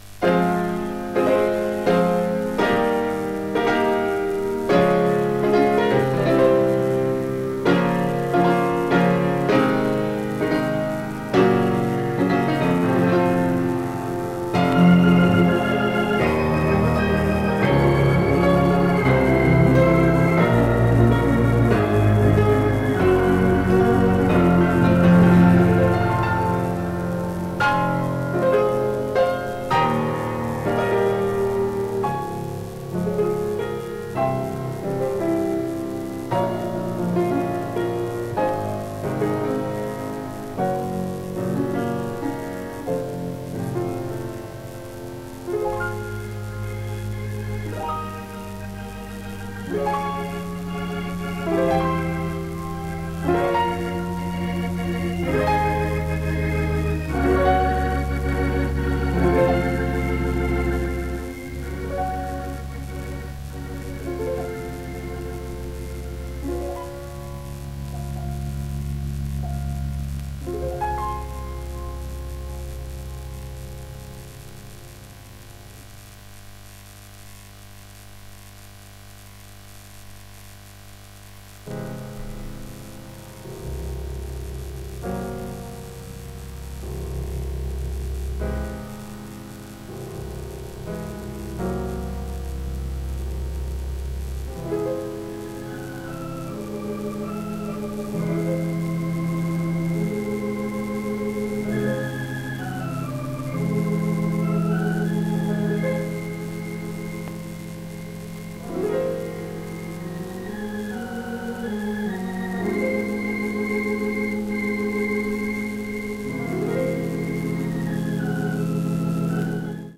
※レコードの試聴はノイズが入ります。